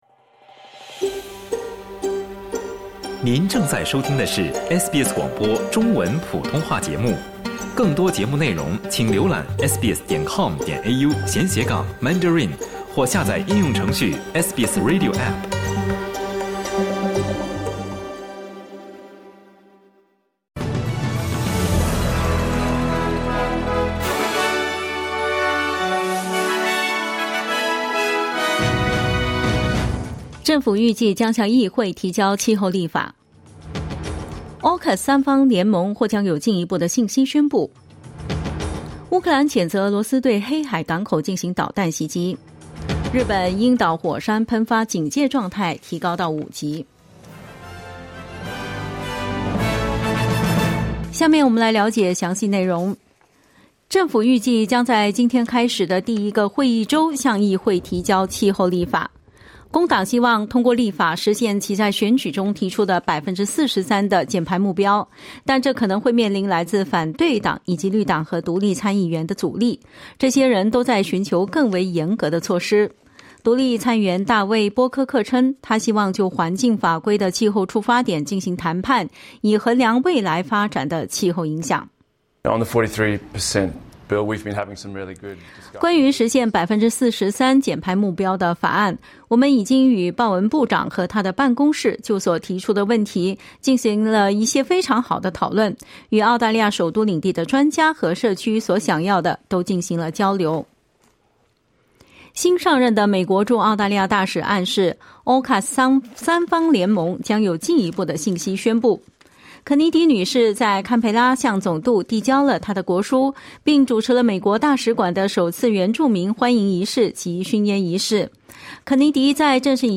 SBS早新闻（7月26日）
请点击收听SBS普通话为您带来的最新新闻内容。